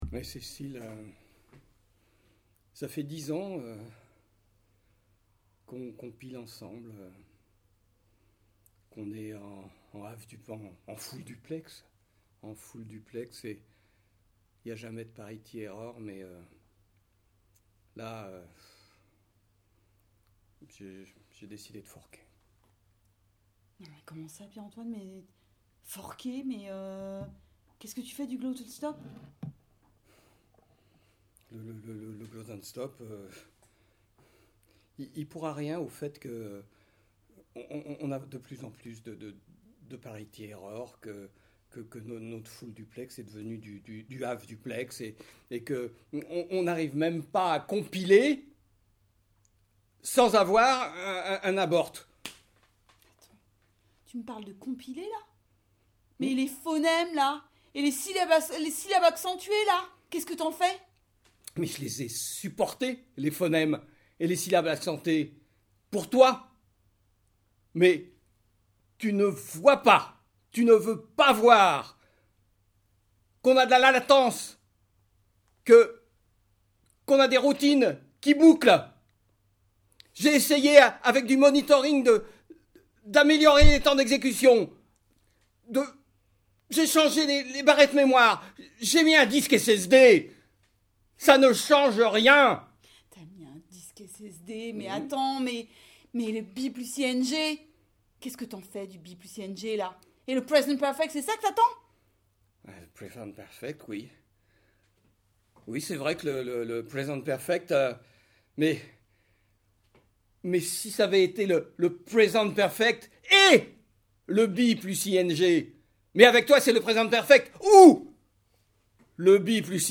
Des fragments de "théâtre improvisé" immatériels, basés sur les relations textuelles.